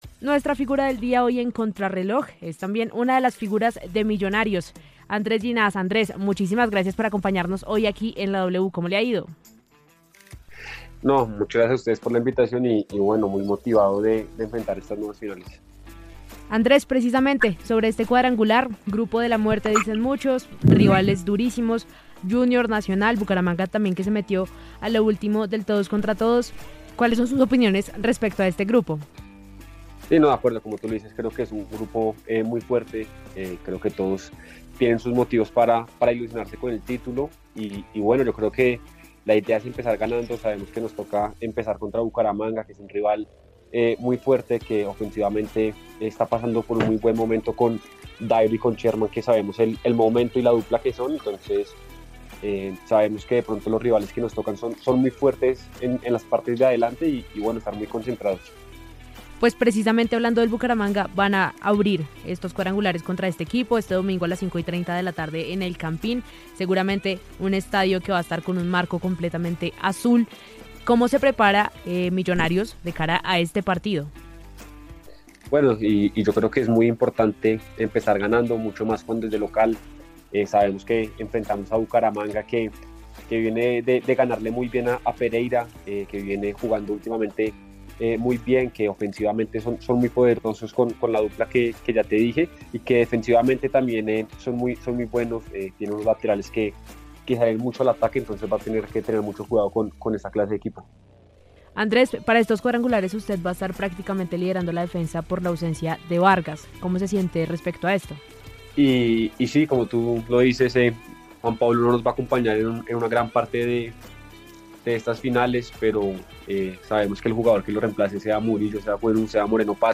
El defensor central de Millonarios, Andrés Llinás, habló con Contrarreloj de W Radio sobre el buen momento del equipo ‘Embajador’, las ganas que hay en el equipo de ser campeones y su futuro en el cuadro que dirige Gamero.